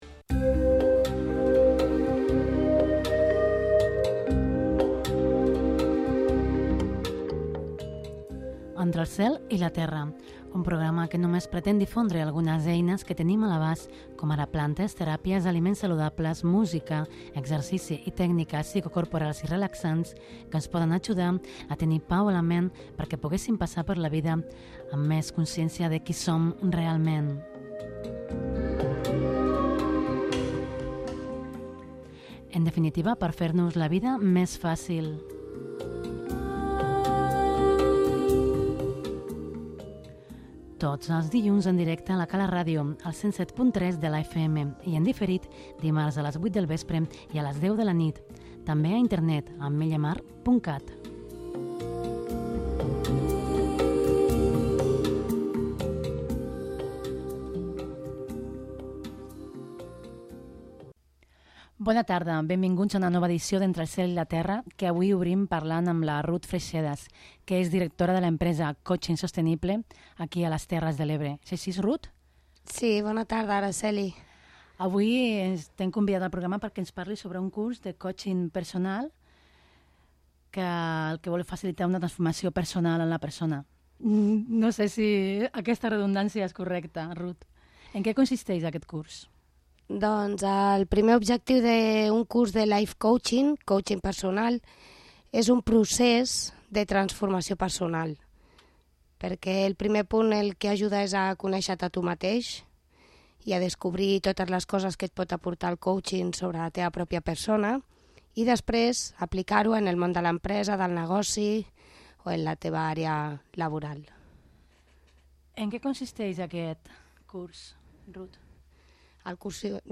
Obrim una nova edició d'ECT amb una entrevista